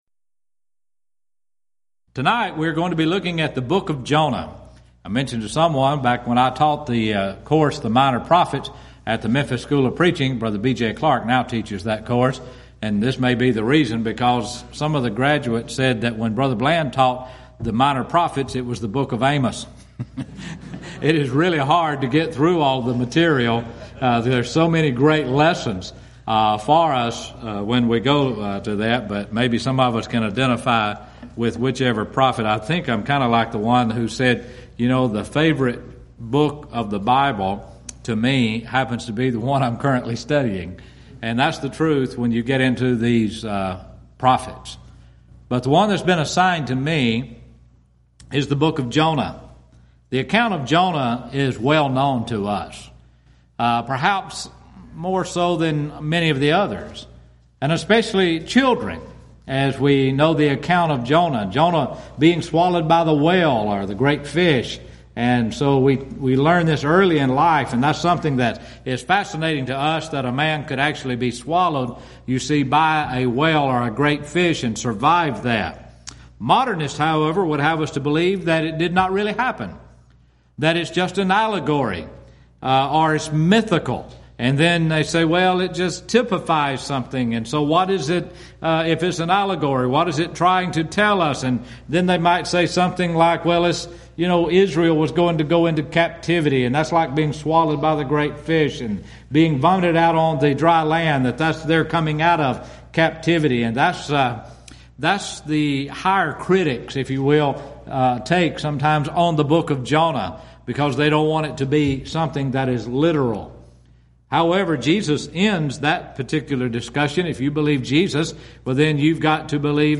Event: 12th Annual Schertz Lectures Theme/Title: Studies in the Minor Prophets